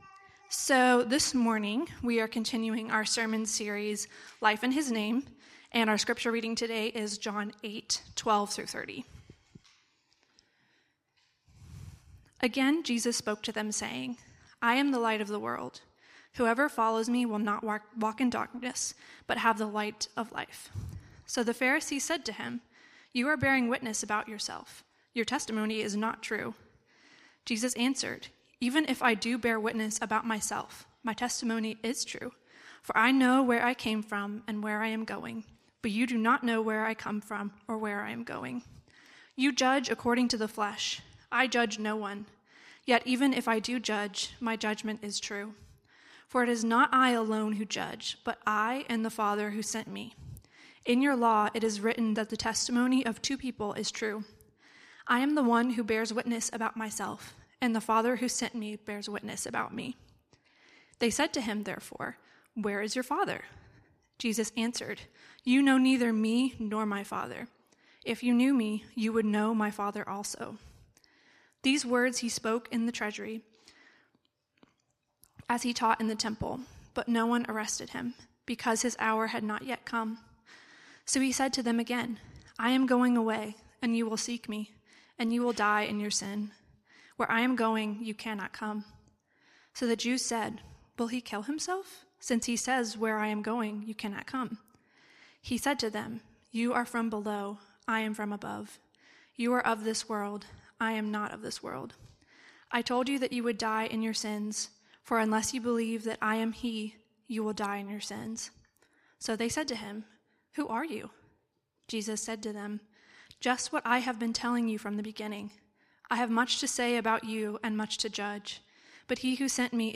Weekly sermons from Redeemer City Church in Madison, Wisconsin, which seeks to renew our city through the gospel.